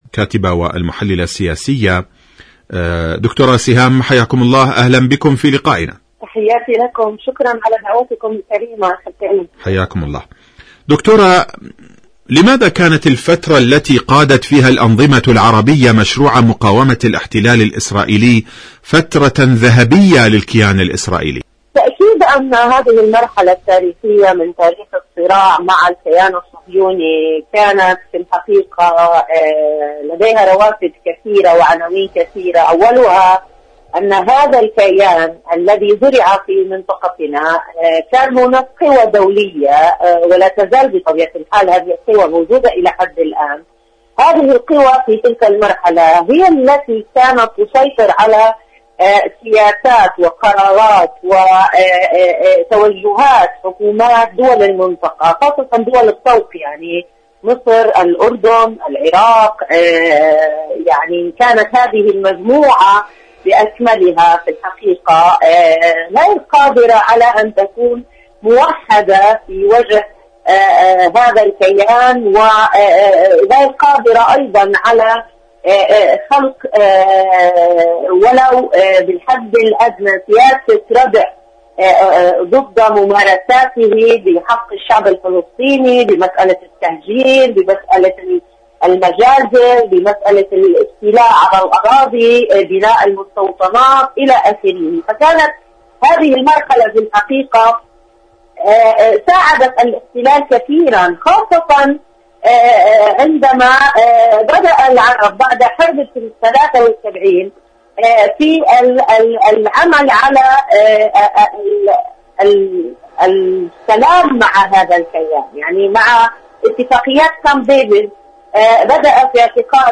مقابلات إذاعية برنامج ارض المقاومة